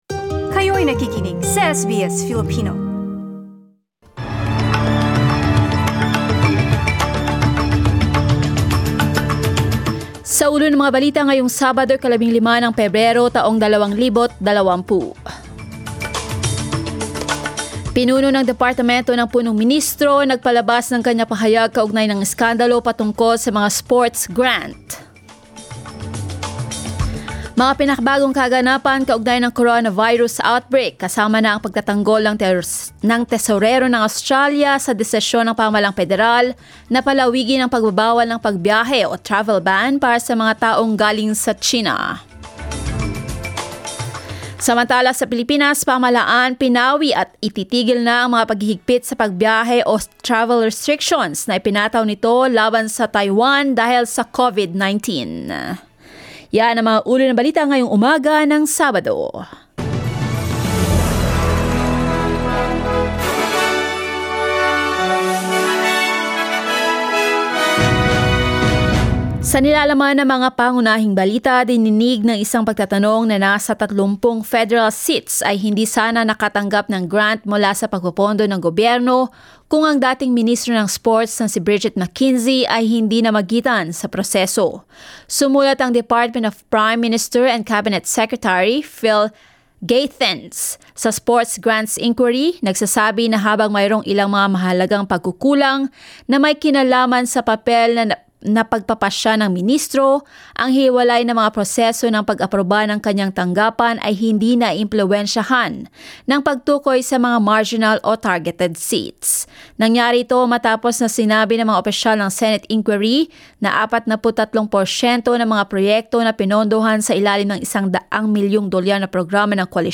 SBS News in Filipino, Saturday 15 February